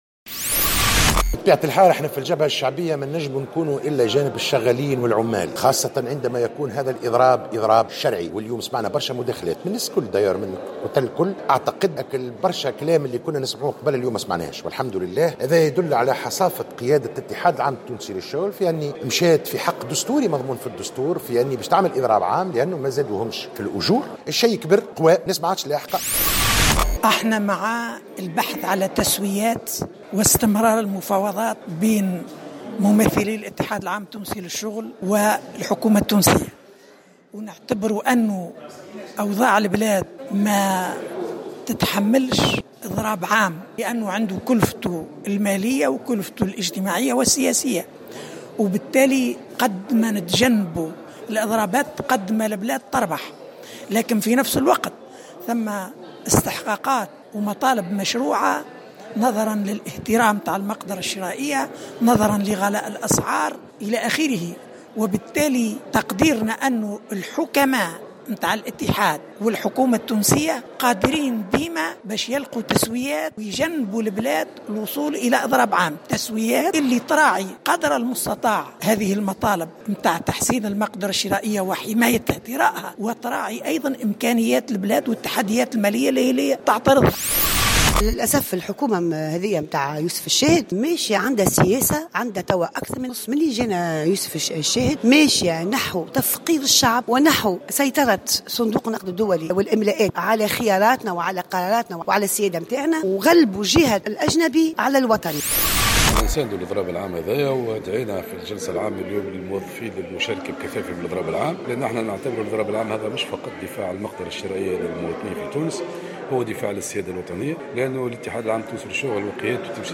آراء عدد من النواب حول الاضراب العام